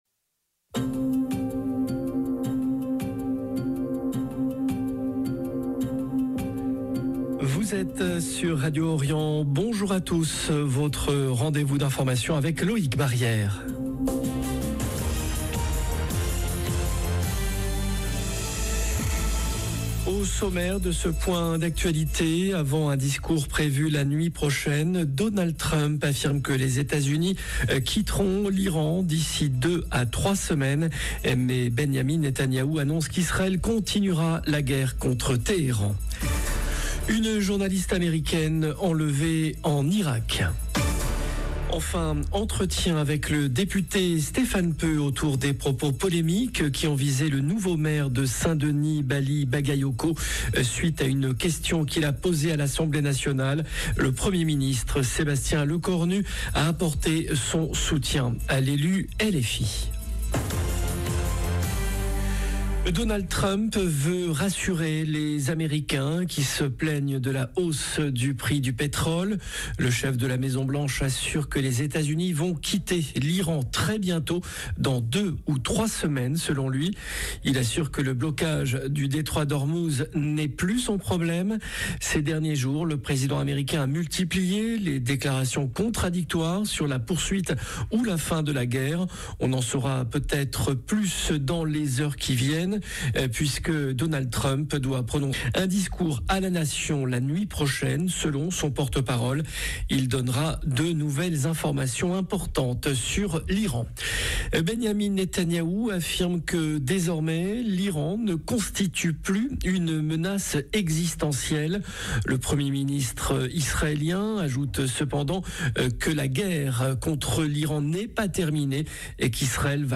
Une journaliste américaine enlevée en Irak. Enfin entretien avec le député Stéphane Peu autour des propos polémiques qui ont visé le nouveau maire de Saint-Denis Bally Bagayoko.